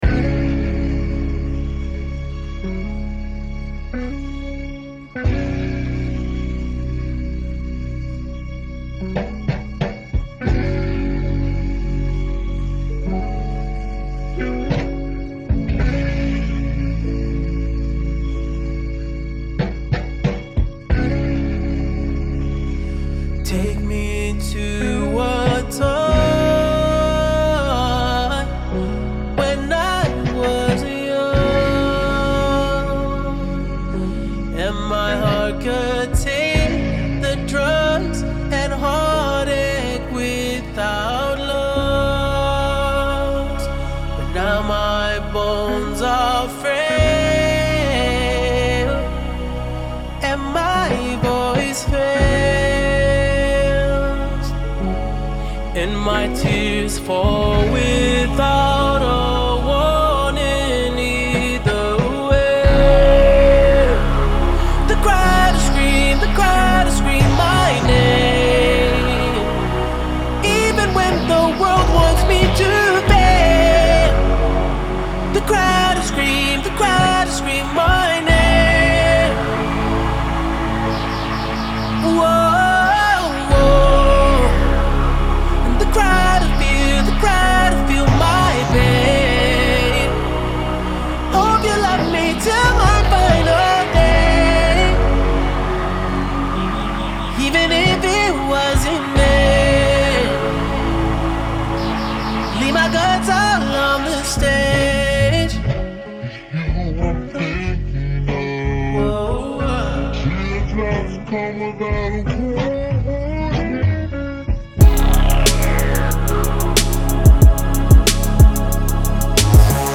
R&B / پاپ / آلترناتیو